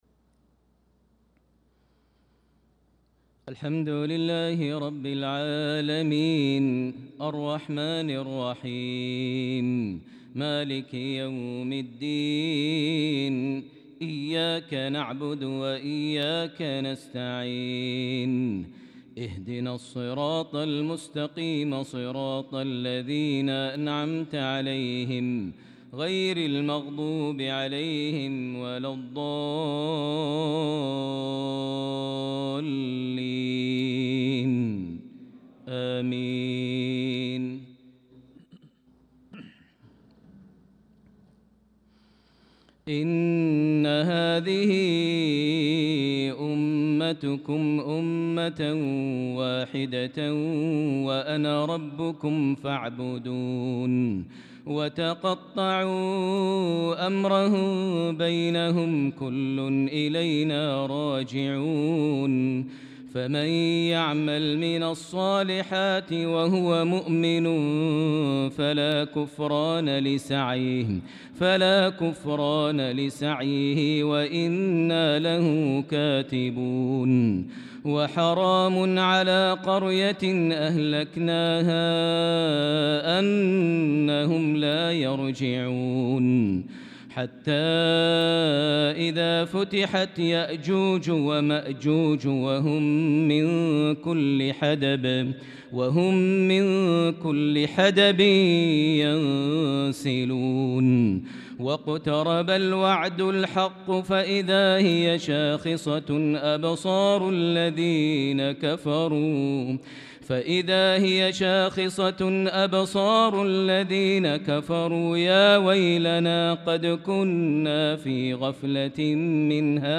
صلاة العشاء للقارئ ماهر المعيقلي 7 ذو القعدة 1445 هـ
تِلَاوَات الْحَرَمَيْن .